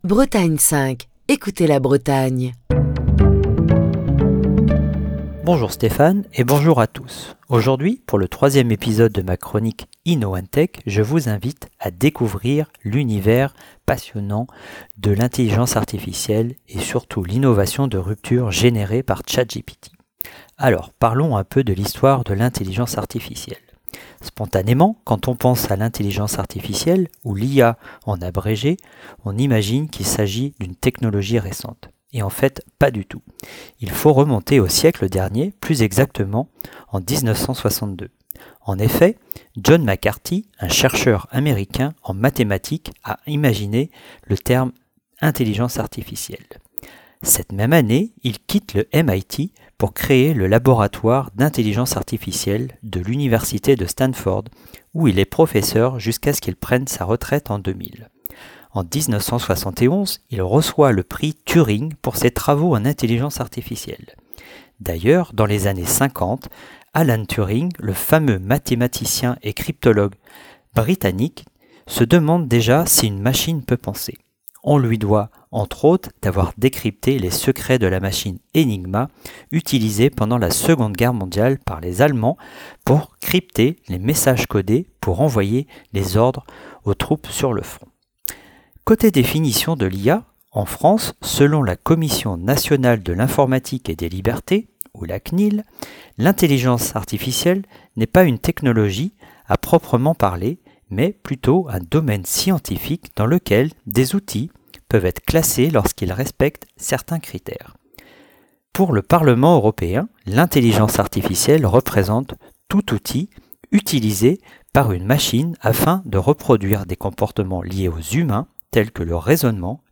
Chronique du 15 février 2023.